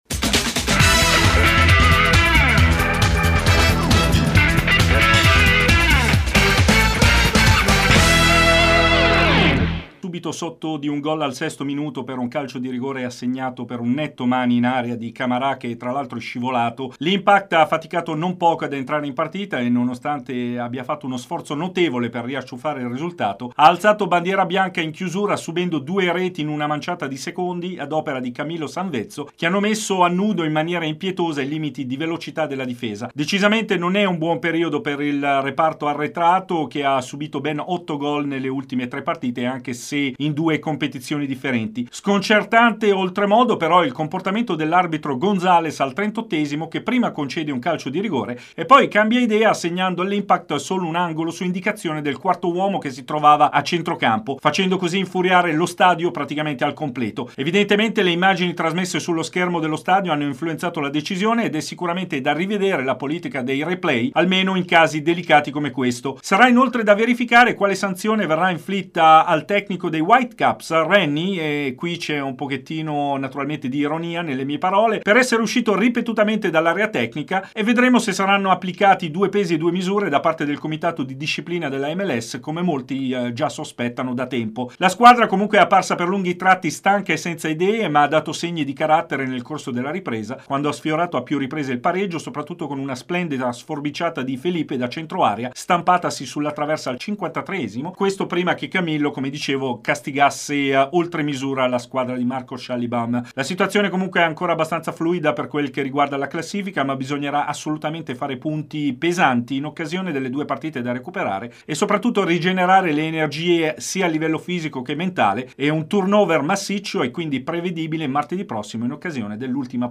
Servizio completo con le inteviste a Schallibaum 1 e 2, Perkins, Di vaio e Ferrari.